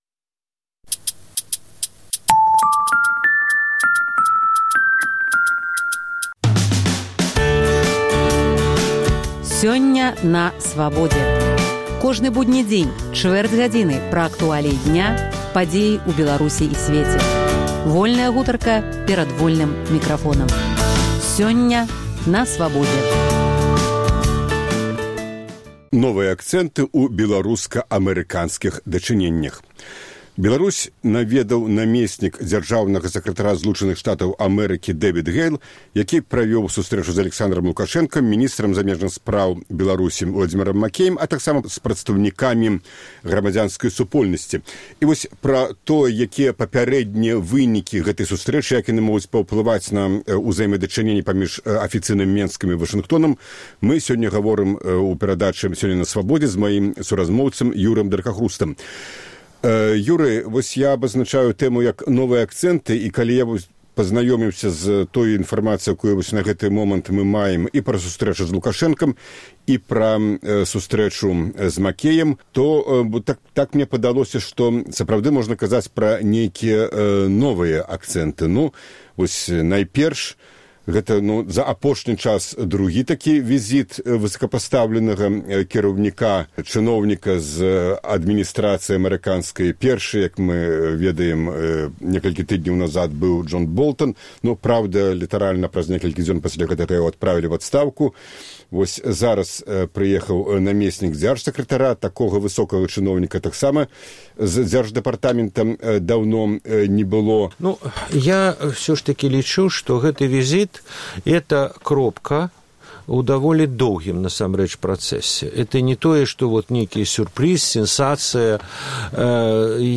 У праграме: Інтэрвію тыдня.